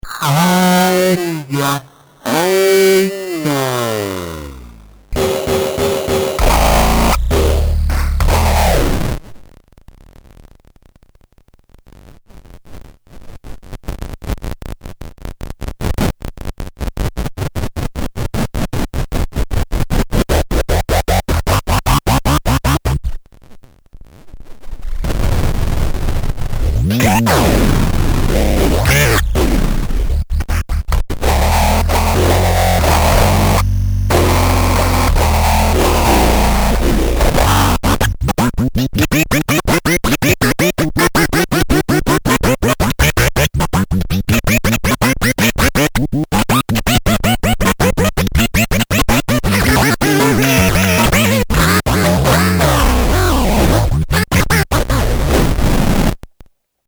Evil lives within this toy guitar. It speaks with the happy voice of Elmo, and sings exultant songs of Sesame Street.
It now sounds much better than the evil it once spewed. Really slow to really fast.